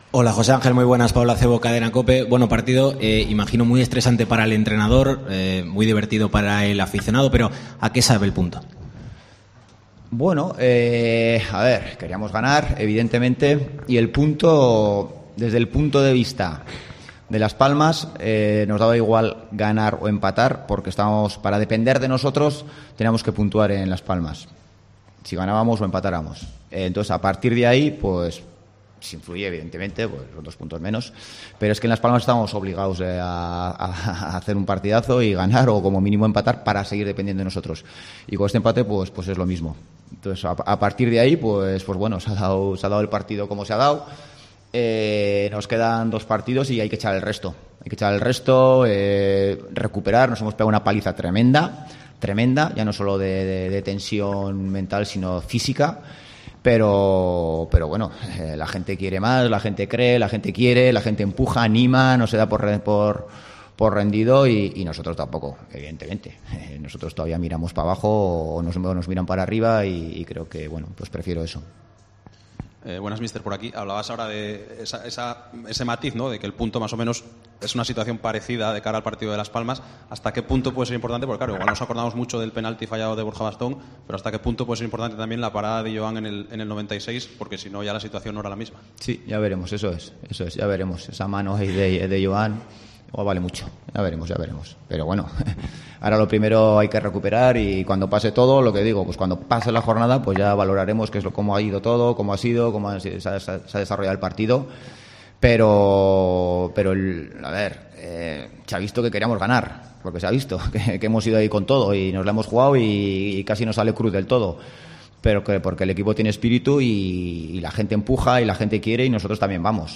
RUEDA DE PRENSA - OVIEDO
José Ángel Ziganda compareció en rueda de prensa tras el empate (3-3) frente al Zaragoza.
Escucha las palabras del técnico del Real Oviedo.